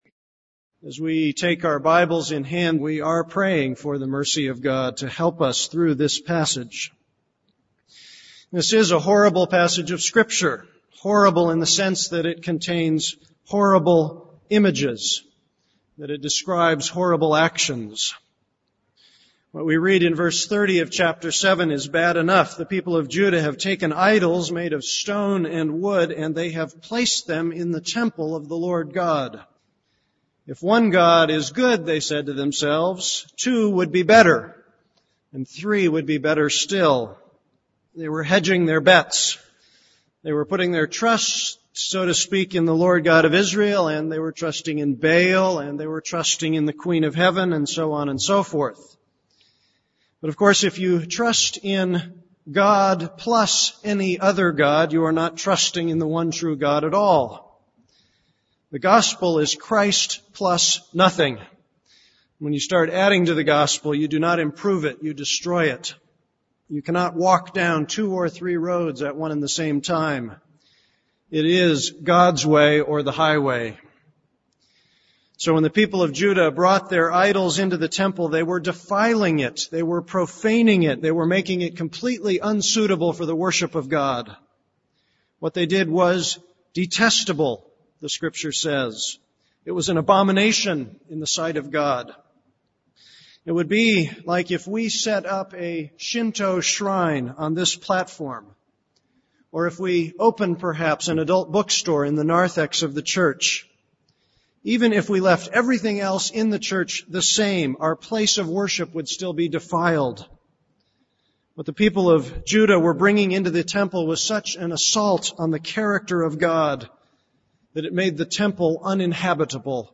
This is a sermon on Jeremiah 7:30-8:3.